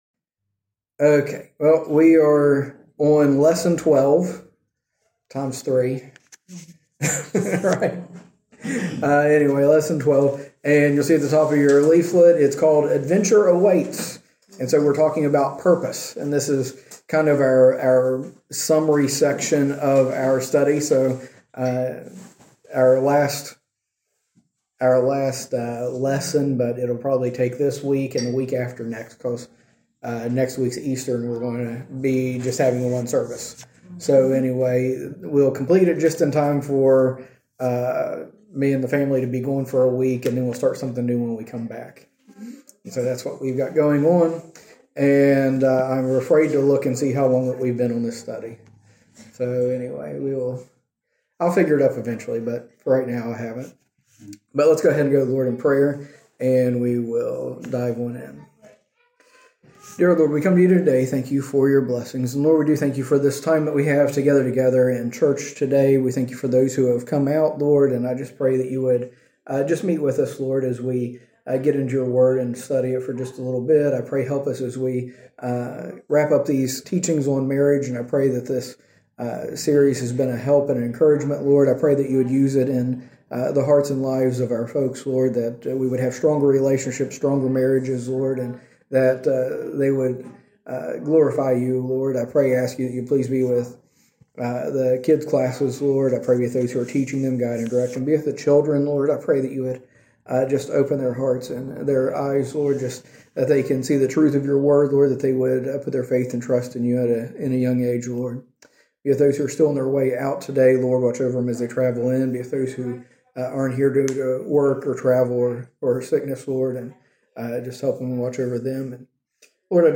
From Series: "Recent Sermons"